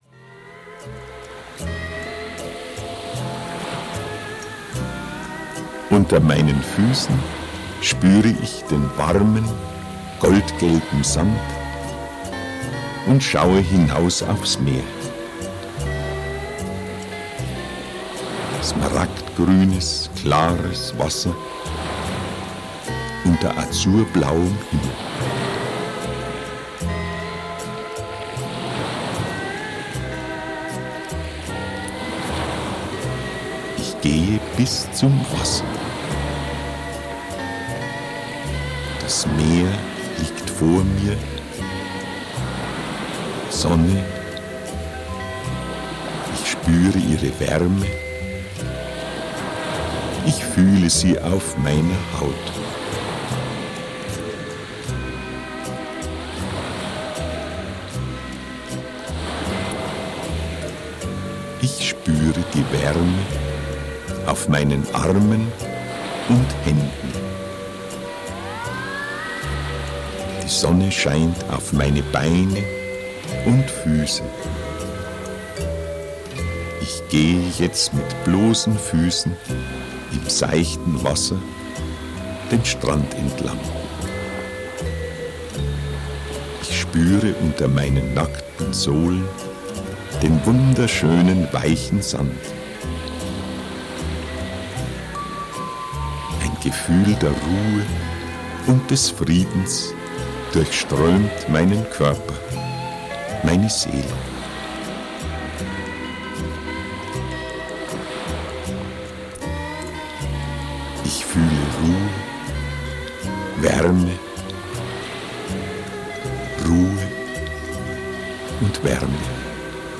Sanfte Hypnose: Entspannungs- Und Imaginationstechniken (Das Soforthilfeprogramm) - Hörbuch